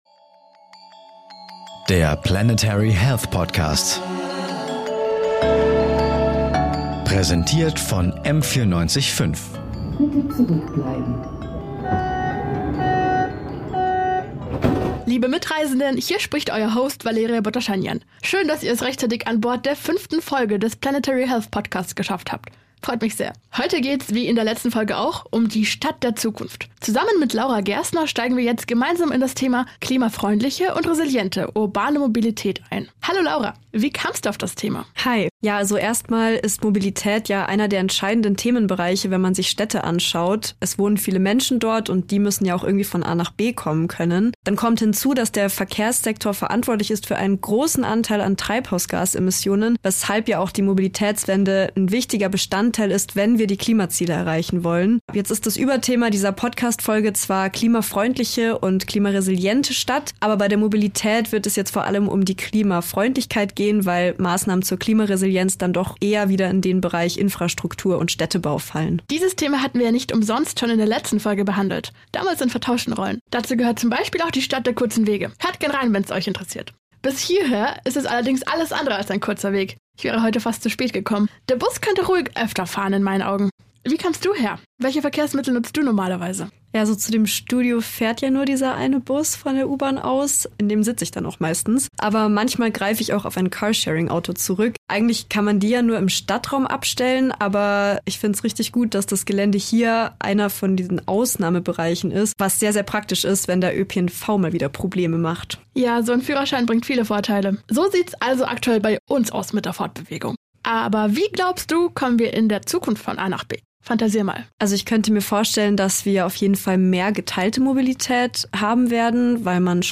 Die Expert:innen erklären verschiedene Mobilitätskonzepte und wie wir diese sinnvoll einsetzen können. Dabei geben sie Einblick in die aktuelle Forschung zu Mobilität in Metropolregionen wie München.